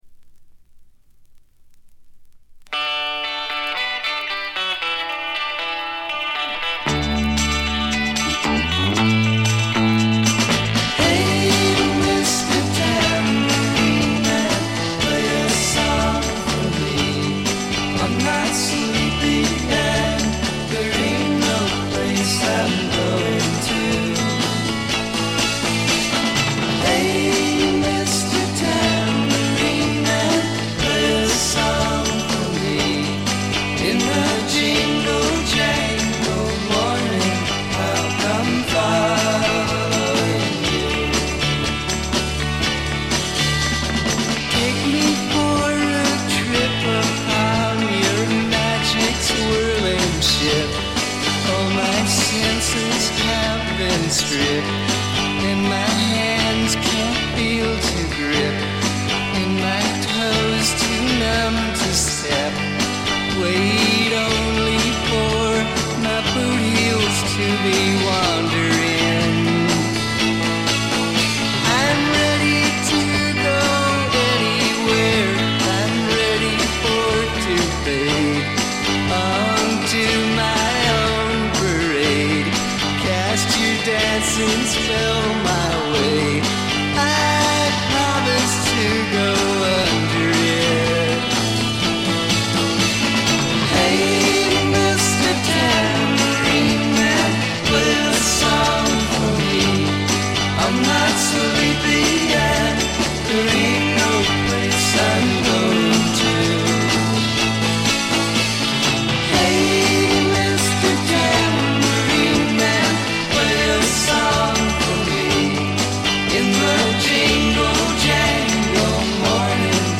バックグラウンドノイズは出てはいますが気にならないレベルです。特に気になるノイズはありません。
モノラル。
試聴曲は現品からの取り込み音源です。